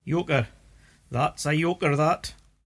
[yOH-ker: that's a yOHker, that!]